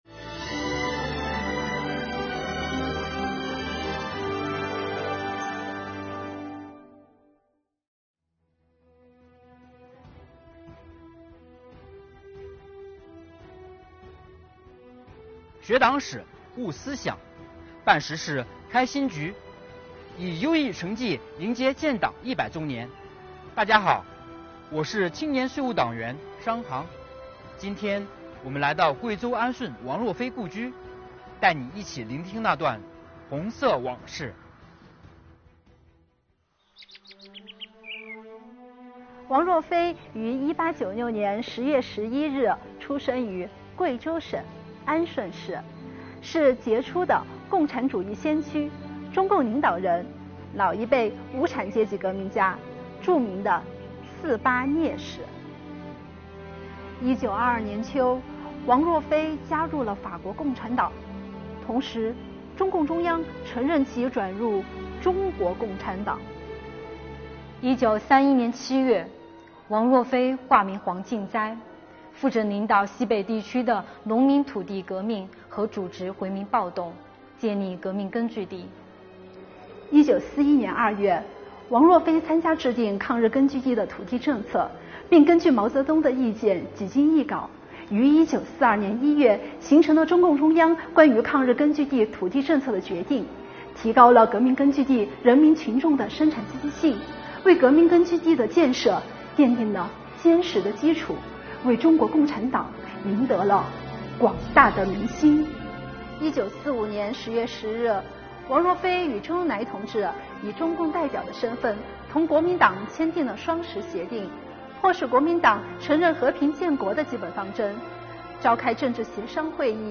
今天，跟随贵州税务干部来到贵州安顺王若飞故居，一起重温他光辉的一生。